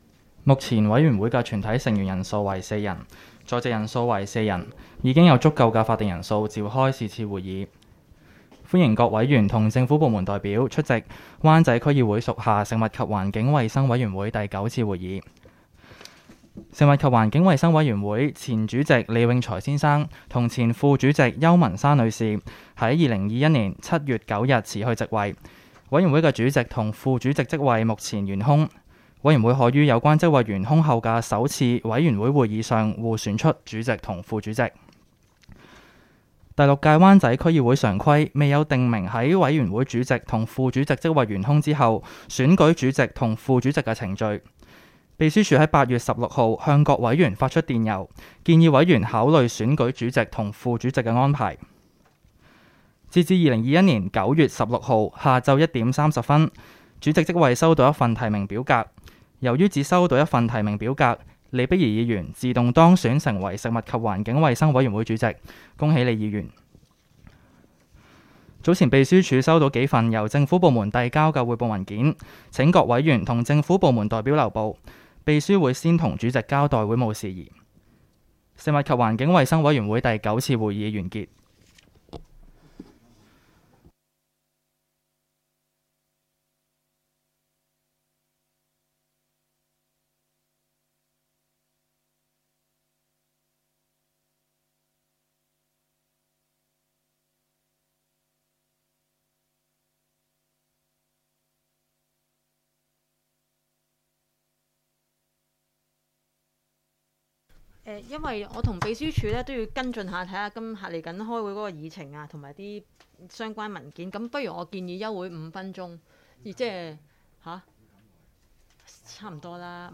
委员会会议的录音记录
地点: 香港轩尼诗道130号修顿中心21楼 湾仔民政事务处区议会会议室